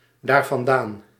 Ääntäminen
Ääntäminen : IPA : /ðɛns/ US : IPA : [ðɛns] Haettu sana löytyi näillä lähdekielillä: englanti Käännös Ääninäyte Adverbit 1. daarvandaan 2. vandaar Määritelmät Adverbit From there , from that place or from that time.